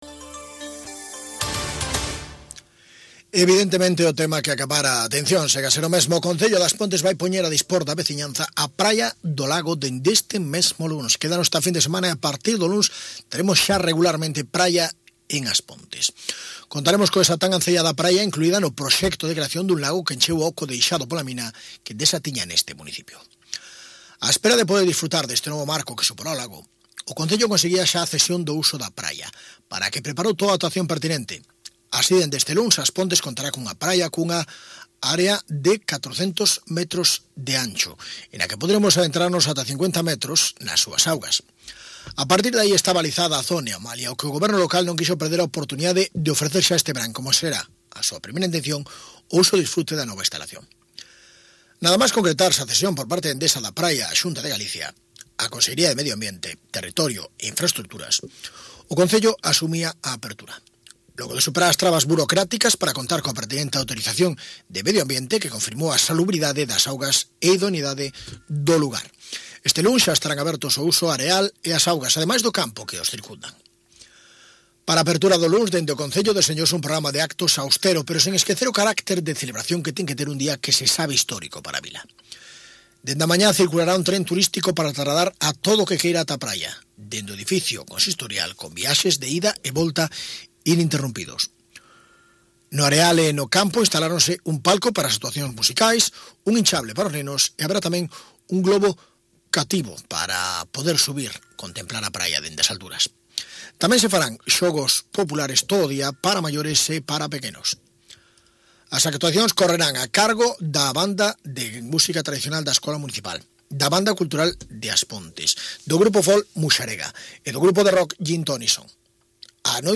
Escoita o informativo de Radio Eume 17 de agosto de 2012 ( MP3 )